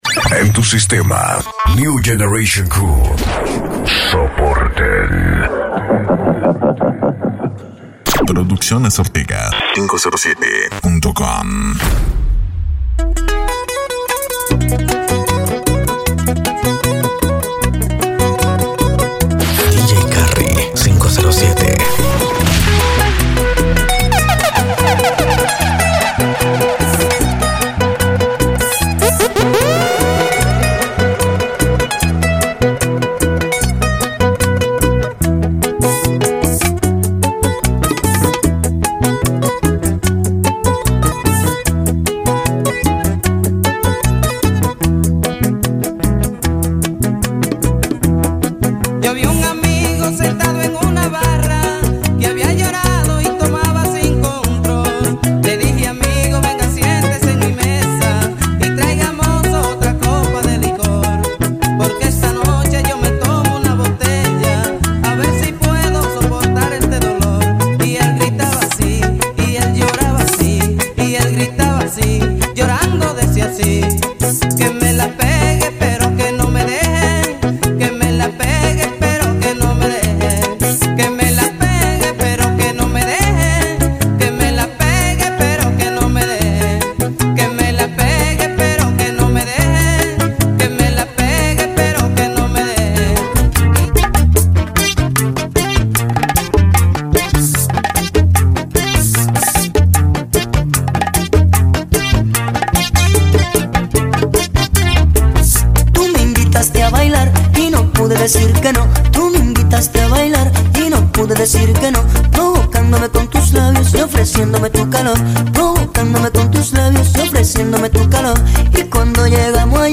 Mixes